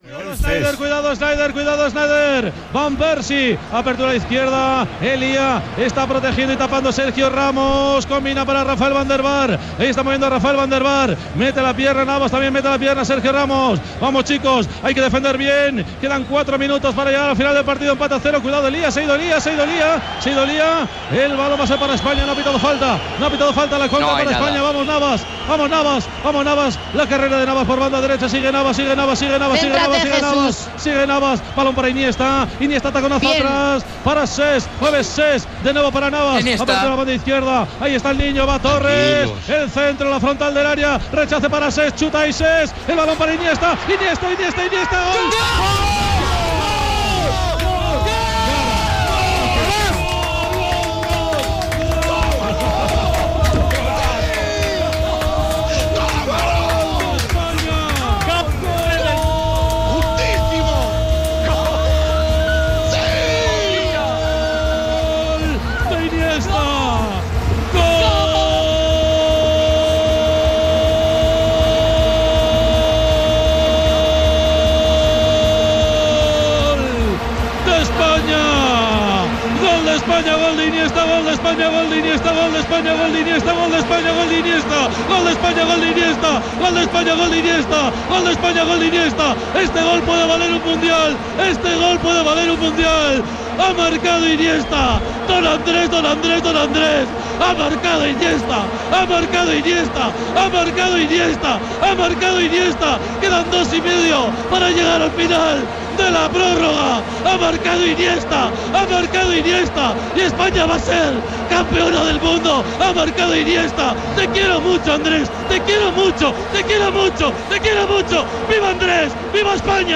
Transmissió del partit de la final de la Copa del Món de Futbol masculí 2010, des de Sud-àfrica.
Esportiu